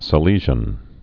(sə-lēzhən, -shən)